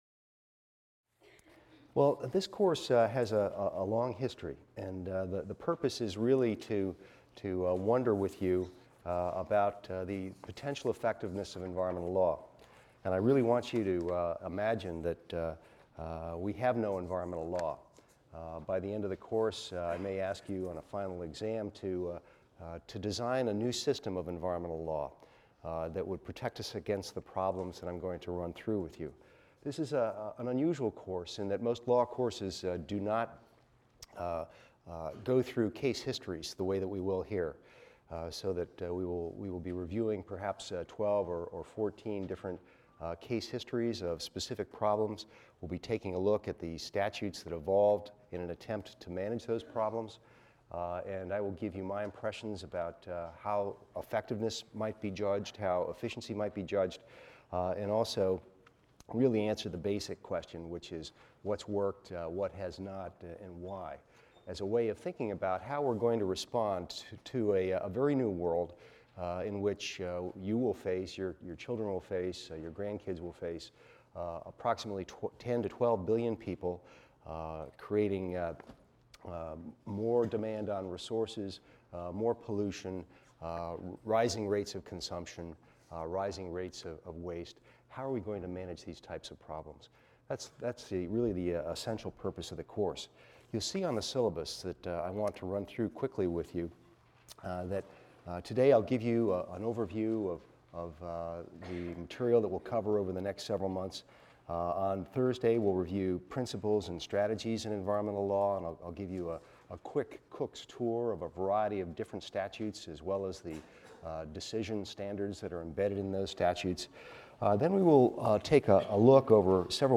EVST 255 - Lecture 1 - Introduction to the Course | Open Yale Courses